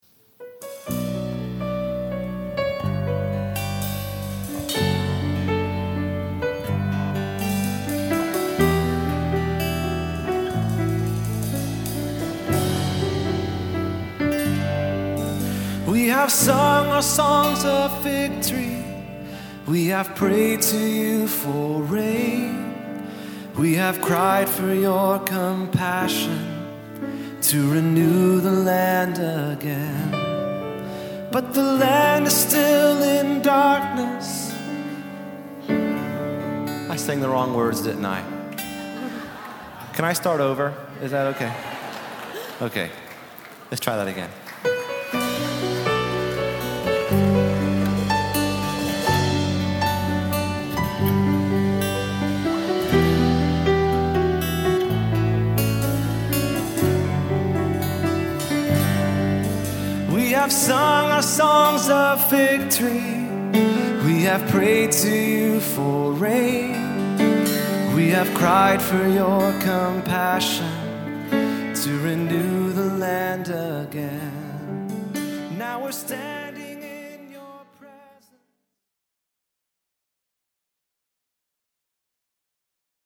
So I started the song off on piano, and then began the first verse with no problem.
The second problem is that I’m the only person singing, so there’s no covering this mistake up.
I was so confused and lost that I had no option other than to just stop and start over again.
My congregation responded to my little blooper with laughter, encouragement, and applause.